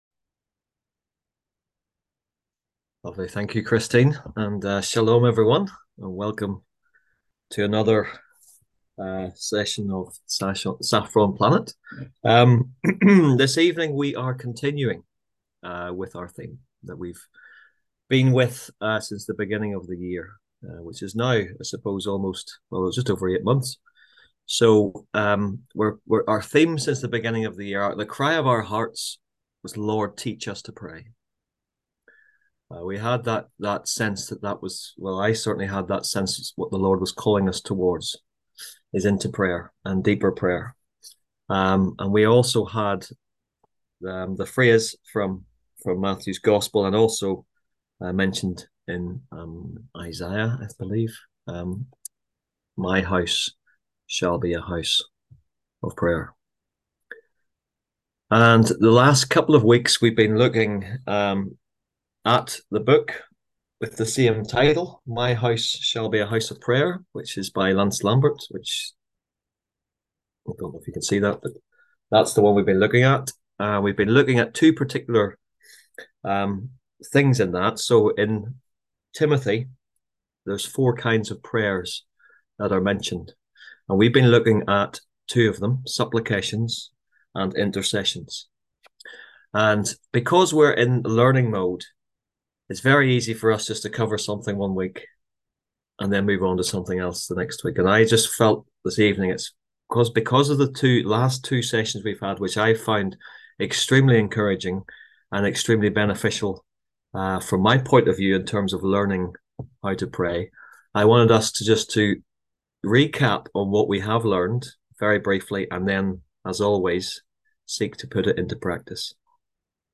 On September 4th at 7pm – 8:30pm on ZOOM ASK A QUESTION – Our lively discussion forum.
On September 4th at 7pm – 8:30pm on ZOOM